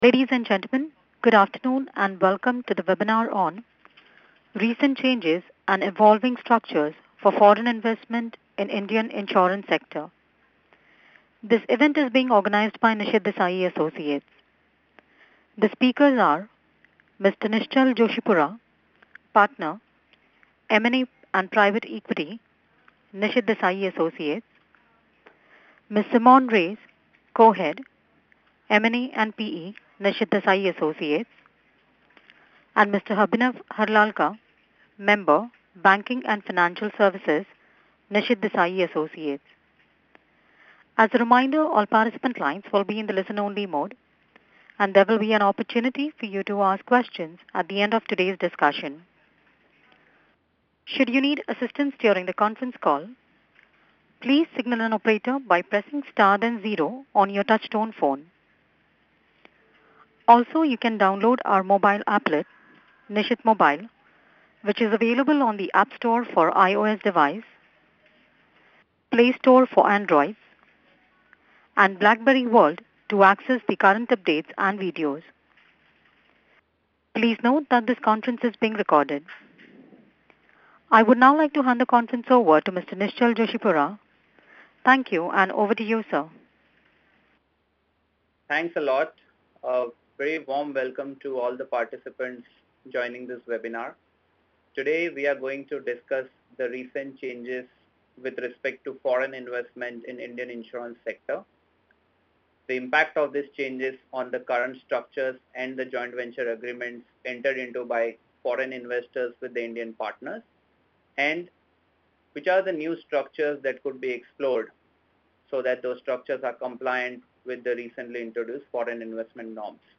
Webinar: Recent Changes and Evolving Structures for Foreign Investment in Indian Insurance Sector